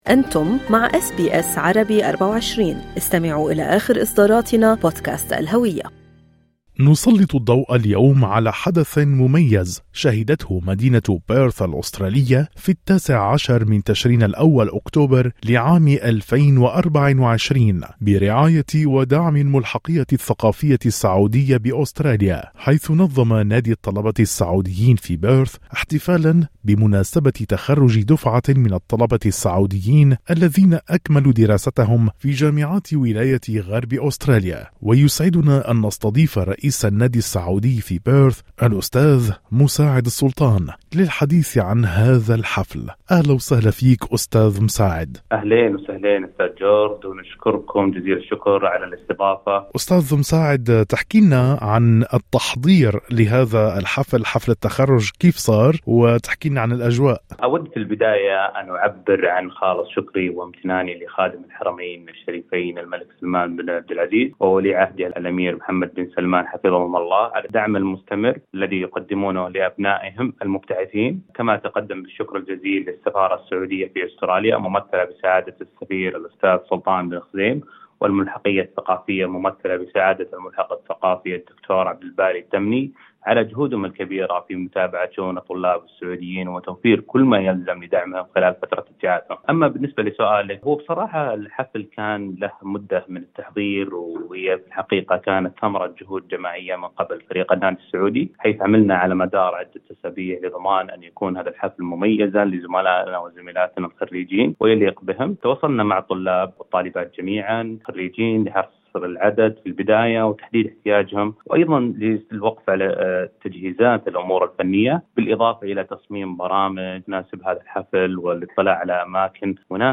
في حديث خاص